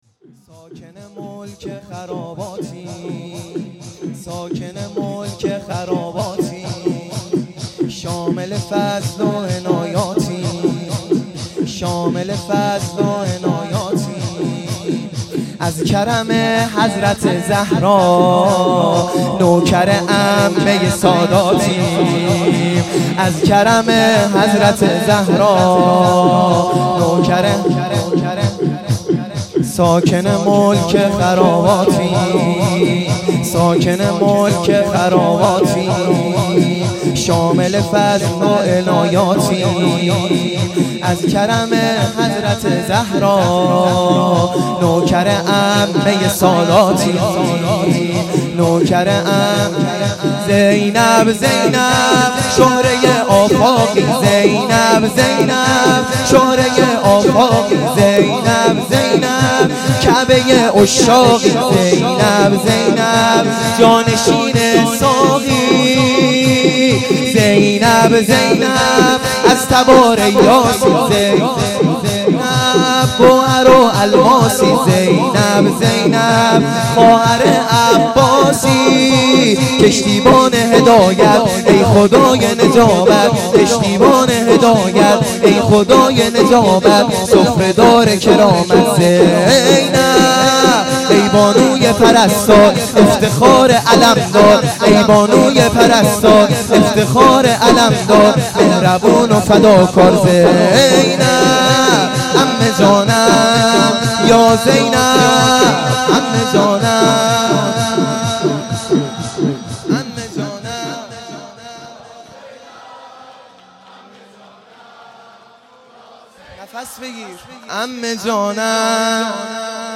0 0 شور | ساکن ملک خراباتیم
شب پنجم محرم الحرام ۱۳۹۶